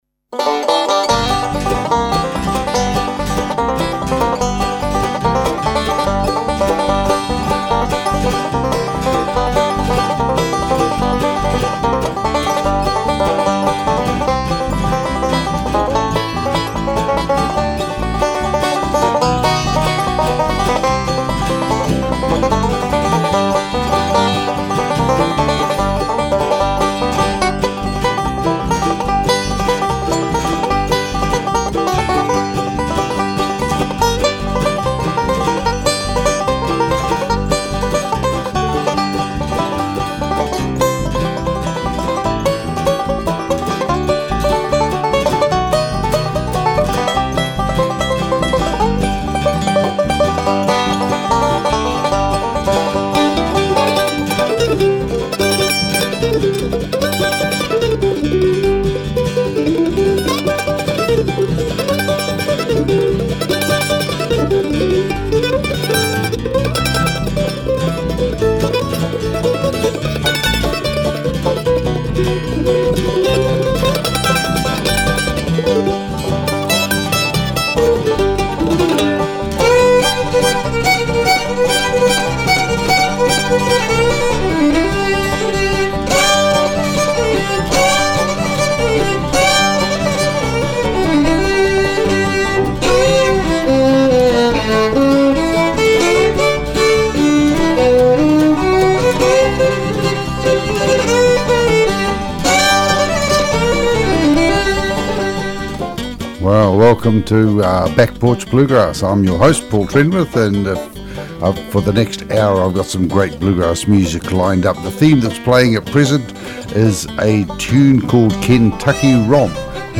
NZ bluegrass show